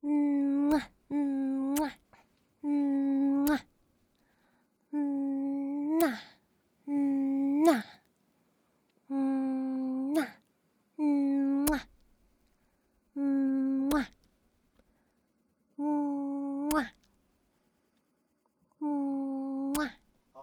亲吻1.wav
亲吻1.wav 0:00.00 0:20.43 亲吻1.wav WAV · 1.7 MB · 單聲道 (1ch) 下载文件 本站所有音效均采用 CC0 授权 ，可免费用于商业与个人项目，无需署名。
人声采集素材/人物休闲/亲吻1.wav